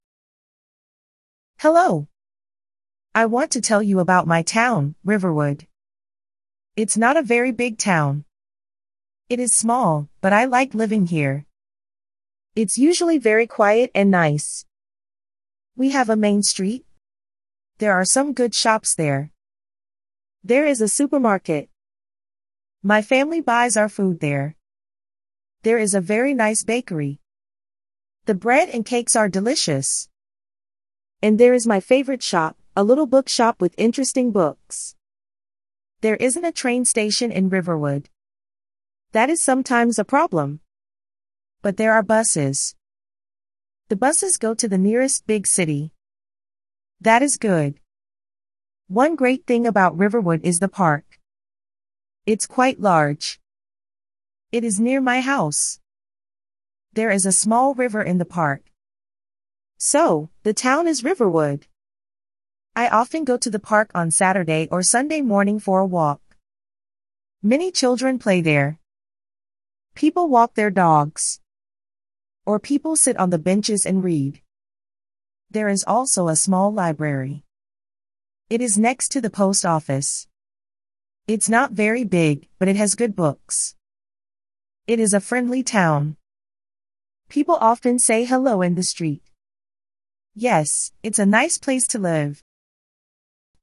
Beginner Listening Practice